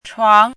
“床”读音
床字注音：ㄔㄨㄤˊ
国际音标：tʂʰuɑŋ˧˥
chuáng.mp3